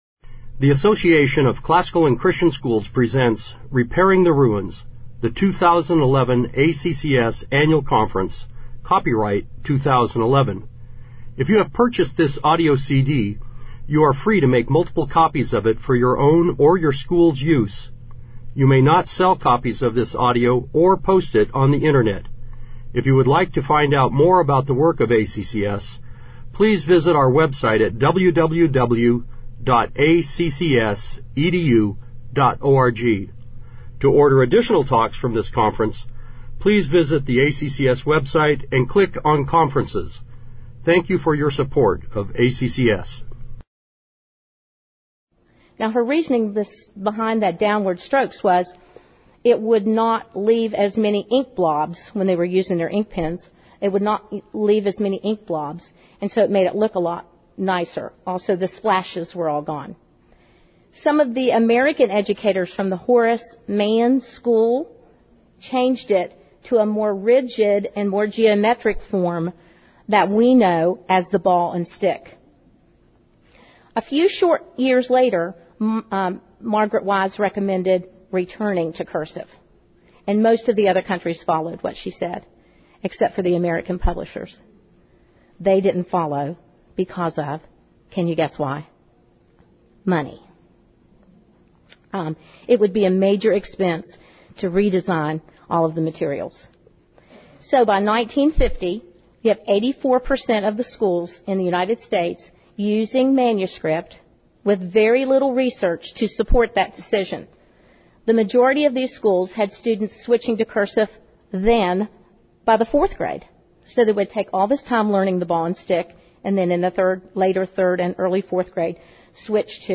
2011 Workshop Talk | 0:41:34 | K-6, Rhetoric & Composition
Speaker Additional Materials The Association of Classical & Christian Schools presents Repairing the Ruins, the ACCS annual conference, copyright ACCS.